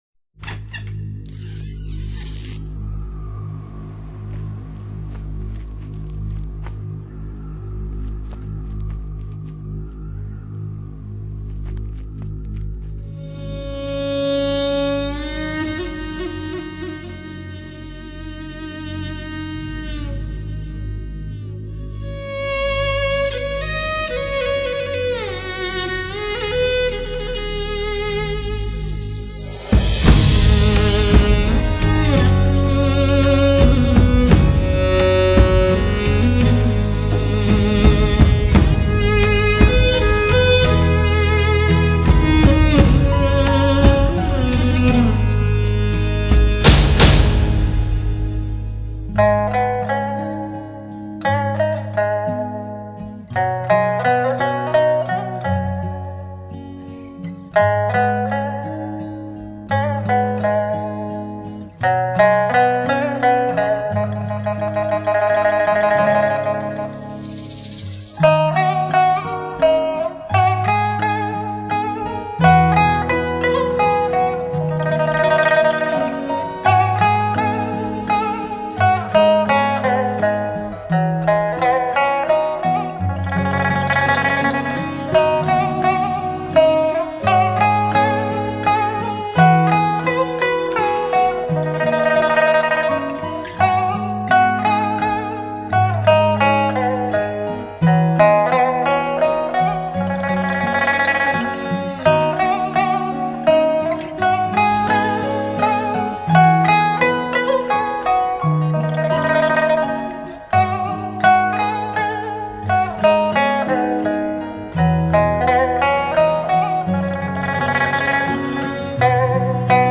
如印象派音乐的清新深刻,影视剧配乐般的张力
情感丰沛的旋律,忽转重,在转轻 弱 ,呈现象光一样的色彩
从容不迫又雍容细致,像一个风华内蕴的美人.....
大量运用中国古典乐器，颗颗音符以优雅的姿态不停呈现；大胆注入现代音乐创作的非凡空间，于是现代的西洋乐器也有了用武之地。